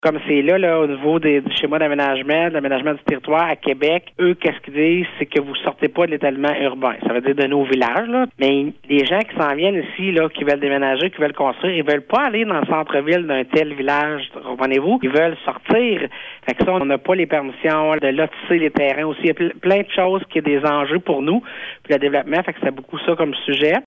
L’un des sujets abordés a été les schémas d’aménagement qui donnent du fil à retordre à la majorité des MRC. La préfète de la MRC Vallée-de-la-Gatineau (MRCVG), Chantal Lamarche, donne plus de précision :